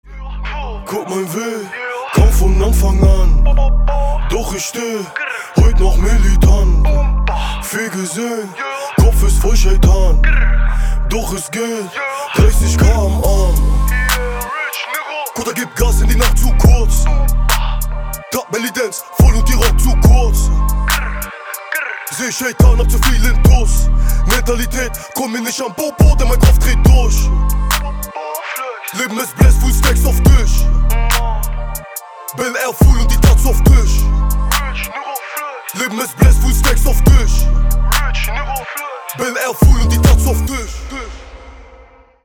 • Качество: 320, Stereo
мужской голос
злые
drill
зарубежный рэп